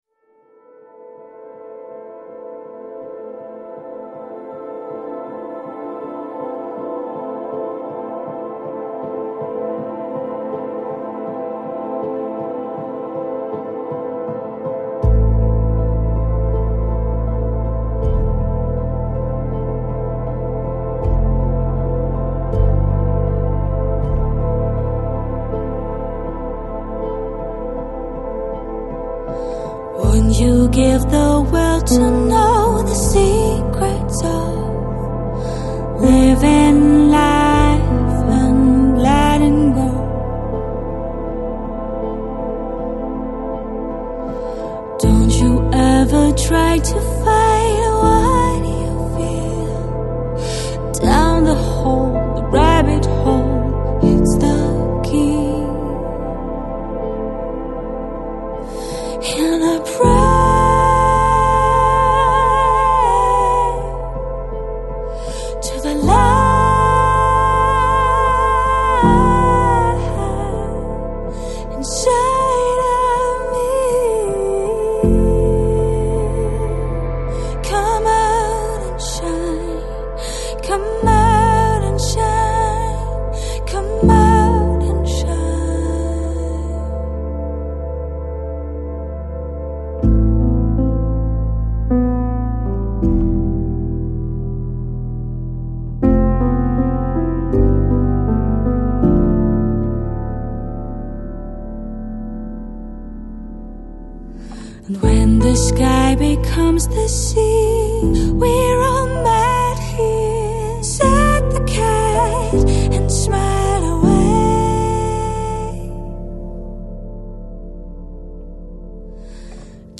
Жанр: Downtempo, Lounge, Ambient